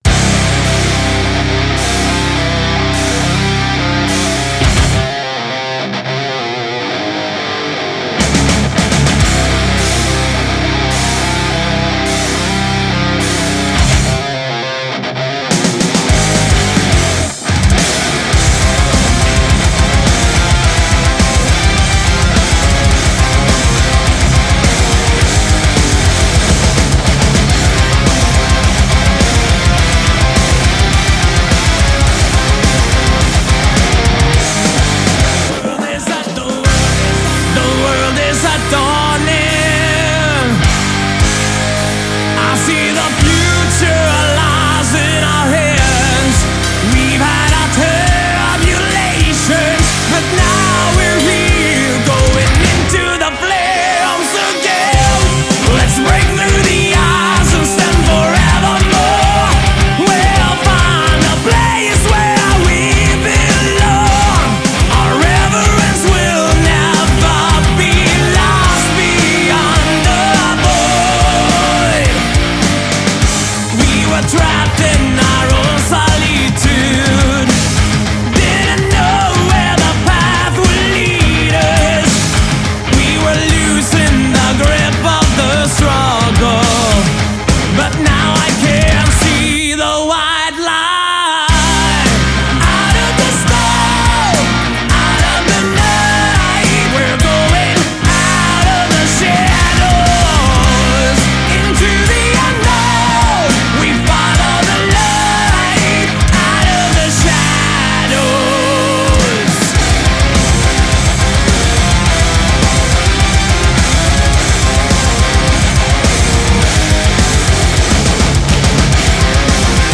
Жанр: Power Metal